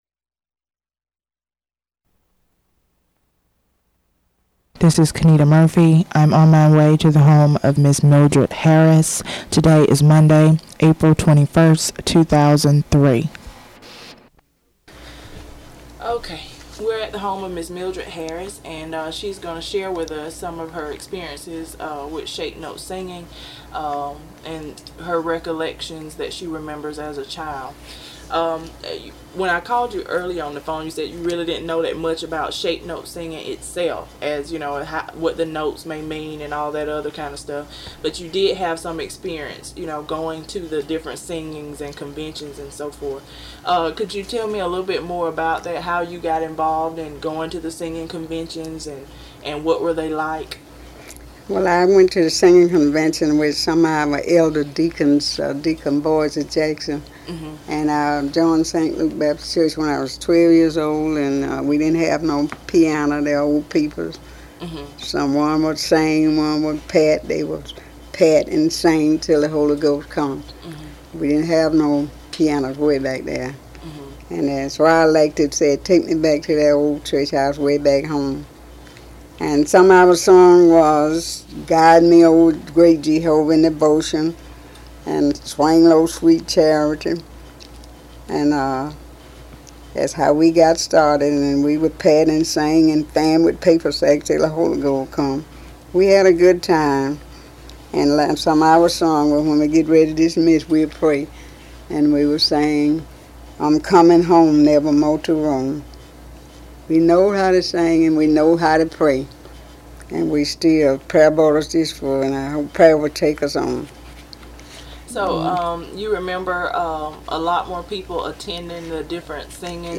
Interview
From the South Georgia Folklife Collection at Valdosta State University Archives and Special Collections.